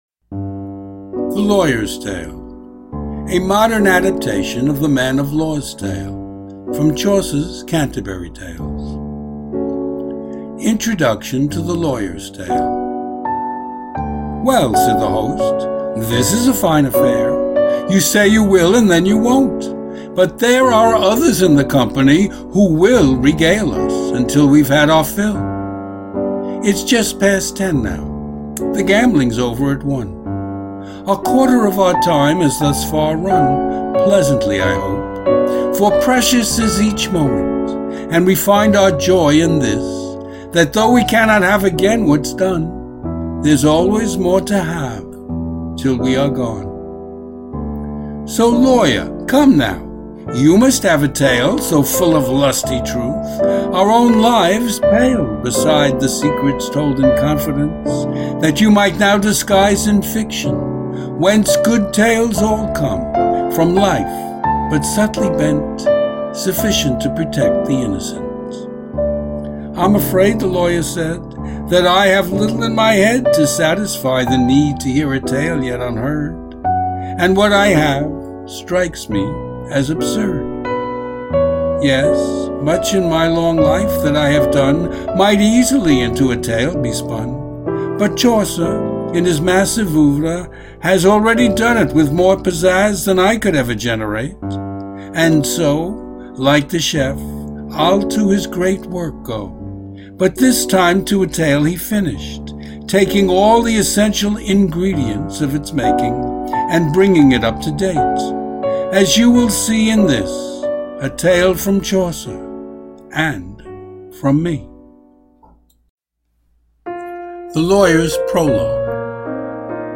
Hear me read The Lawyer's Tale as an MP3 file.